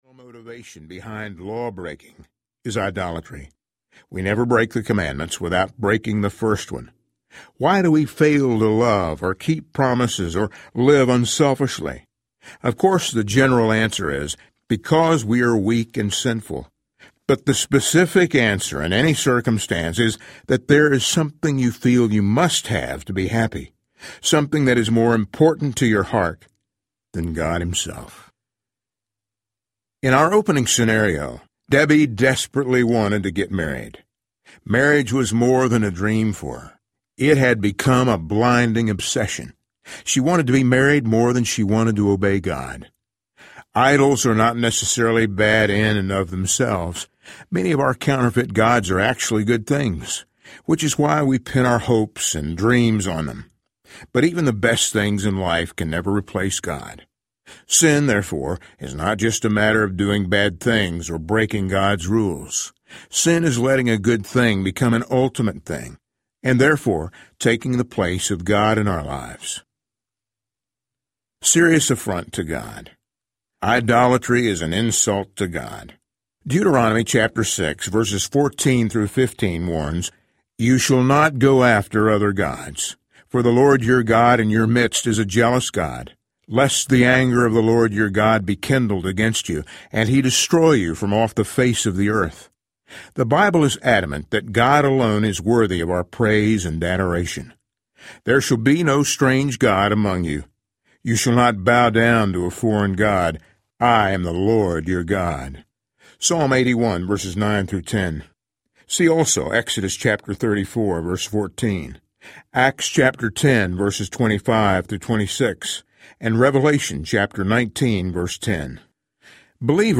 Worship on Earth as It Is in Heaven Audiobook
Narrator
7.1 Hrs. – Unabridged